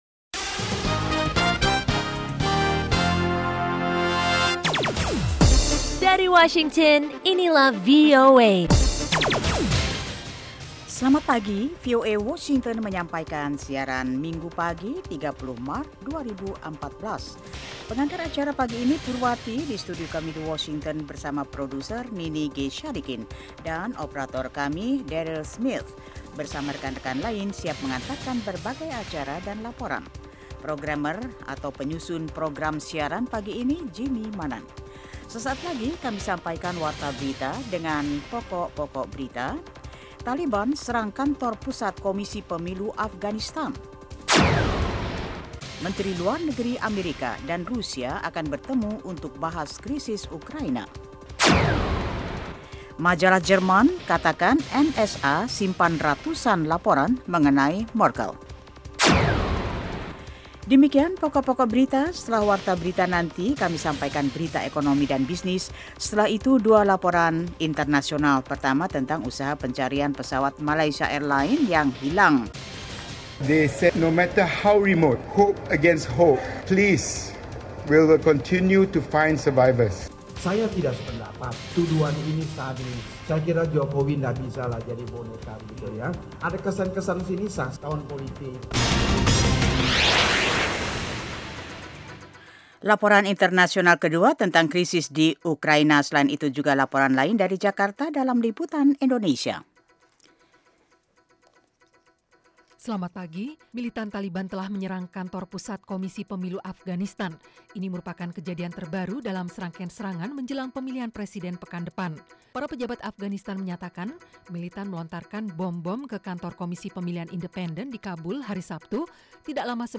Setiap paginya, VOA menyiarkan sebuah program informatif yang menghidangkan beragam topik yang menarik, berita internasional dan nasional, tajuk rencana, bisnis dan keuangan, olah raga, sains dan kesehatan, musik dan tips-tips pengembangan pribadi.